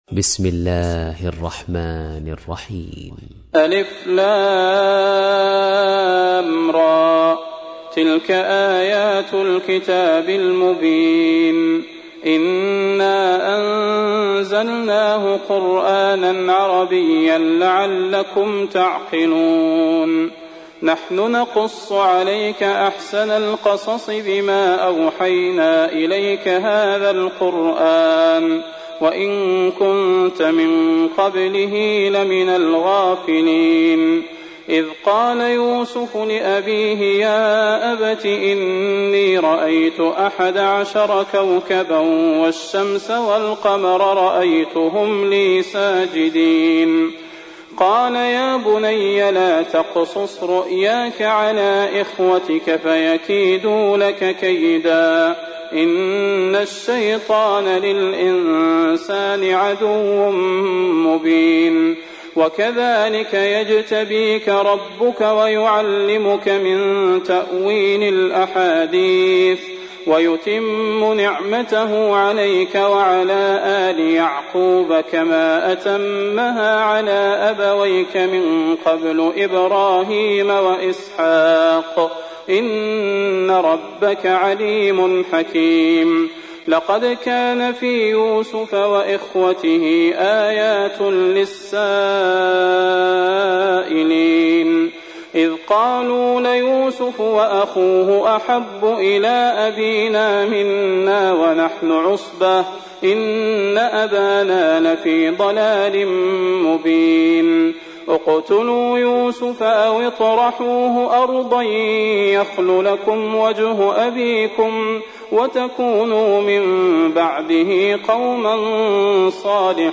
تحميل : 12. سورة يوسف / القارئ صلاح البدير / القرآن الكريم / موقع يا حسين